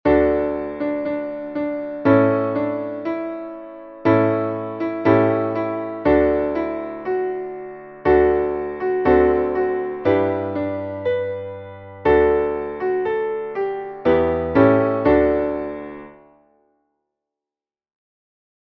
Traditionelles Abendlied (Gebet)